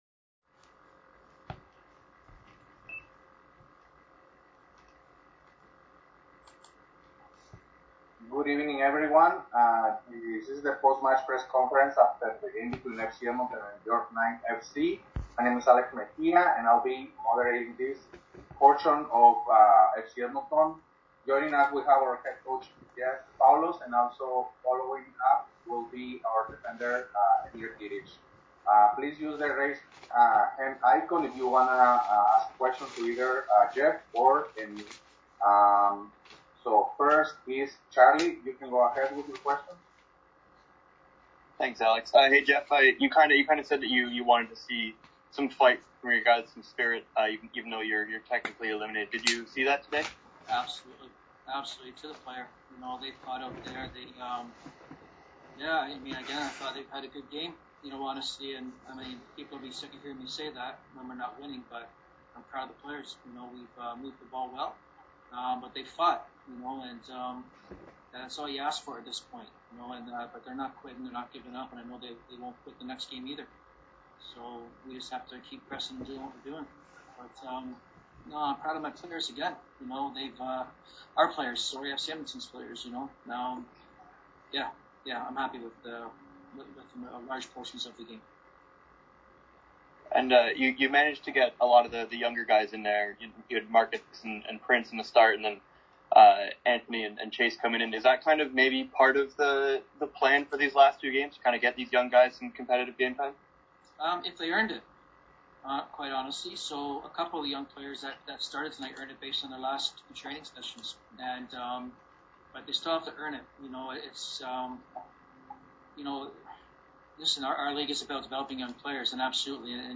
BOX SCORE: Post game press conference conducted via Zoom.